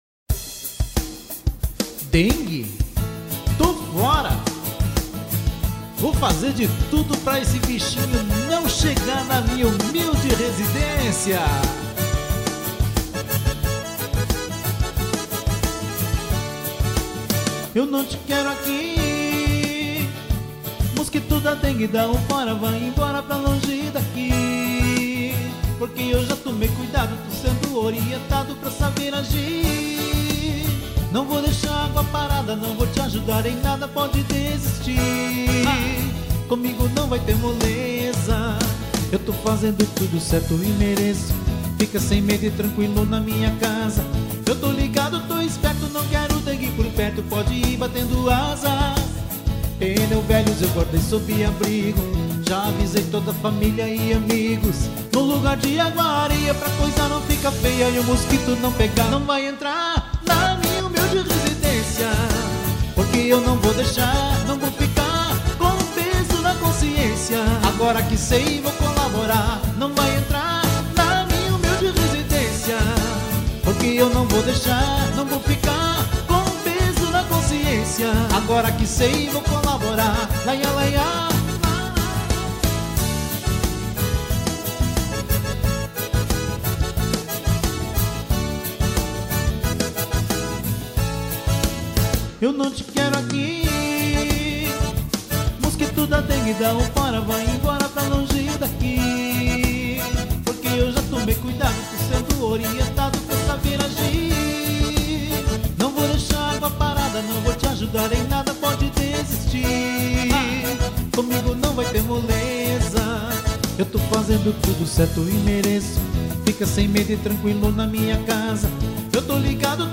13. Tipo: Paródia Musical